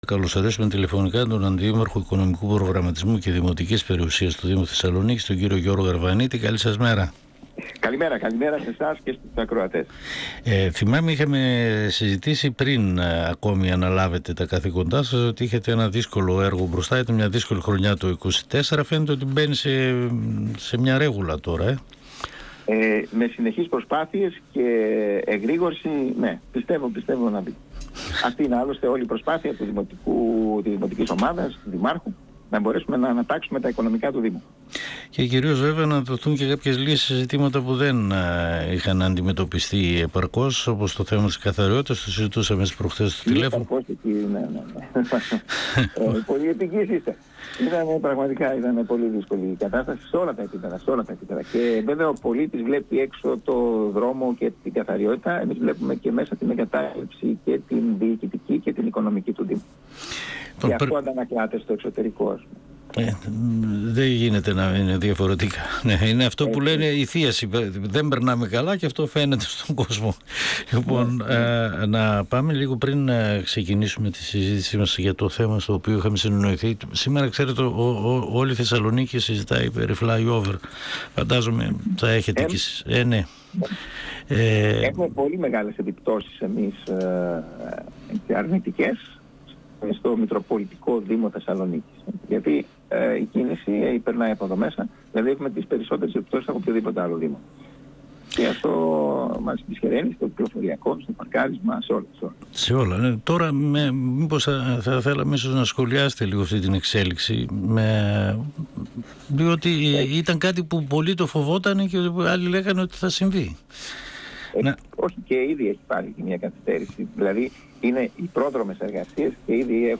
Στην οικονομική κατάσταση του Δήμου Θεσσαλονίκης που παρέλαβε η νέα διοίκηση του Δήμου Θεσσαλονίκη αναφέρθηκε ο Αντιδήμαρχος Οικονομικού Προγραμματισμού και Δημοτικής Περιουσίας του Δήμου Γιώργος Αρβανίτης μιλώντας στην εκπομπή «Εδώ και Τώρα» του 102FM της ΕΡΤ3.